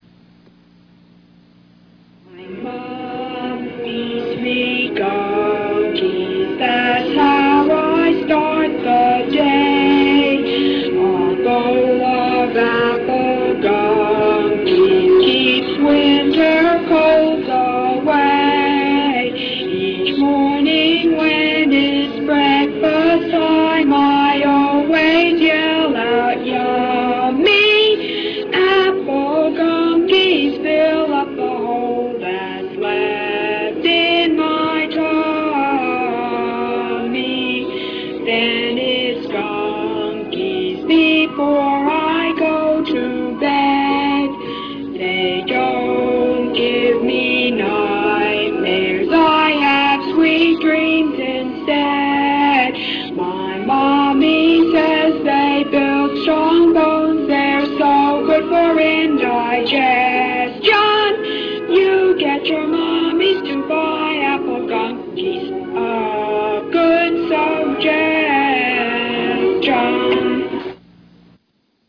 I decided it would be fun to run fake humorous commercials instead.
My Mom Feeds Me Gunkies is set to the noble chorale-like passage in the last movement of Brahms' First Symphony.